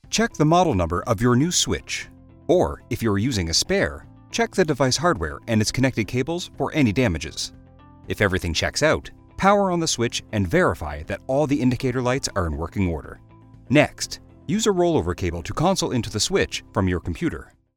Male
I provide a warm, deep range with various styles.
E-Learning
Words that describe my voice are Deep, Warm, Narrative.
All our voice actors have professional broadcast quality recording studios.